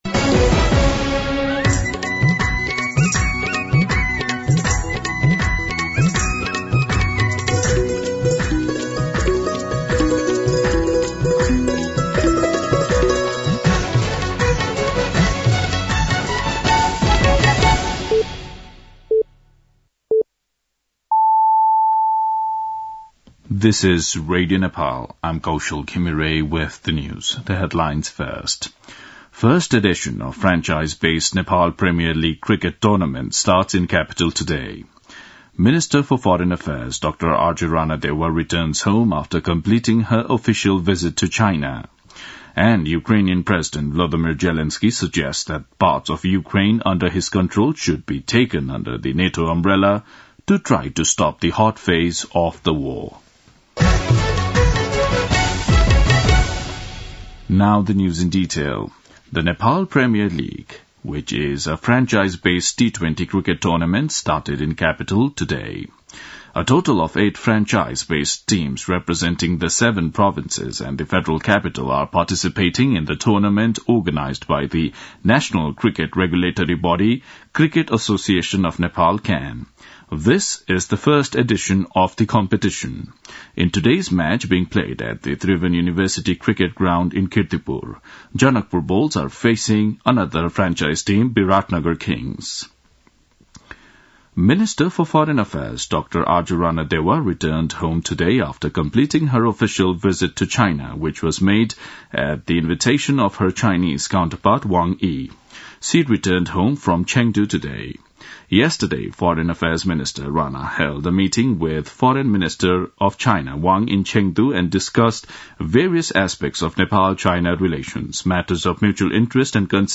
An online outlet of Nepal's national radio broadcaster
दिउँसो २ बजेको अङ्ग्रेजी समाचार : १६ मंसिर , २०८१
2pm-English-News-.mp3